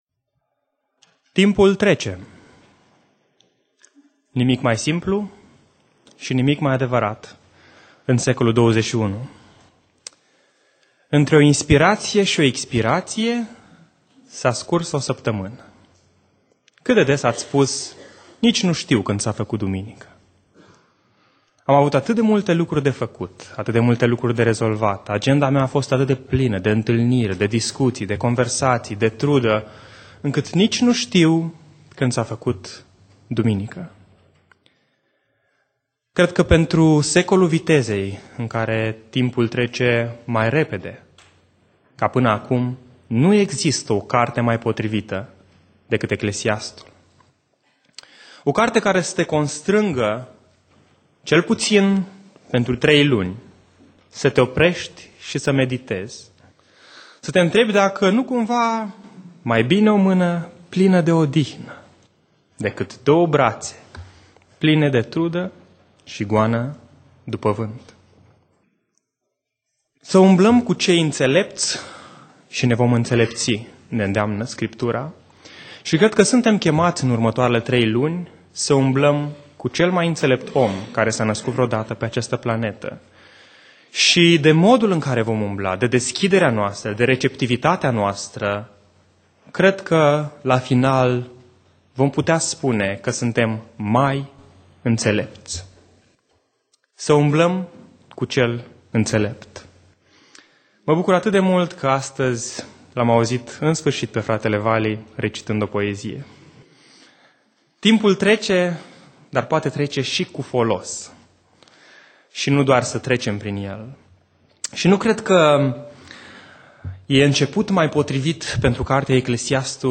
Predica Exegeza-Eclesiastul 1:1-11